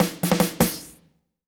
British SKA REGGAE FILL - 17.wav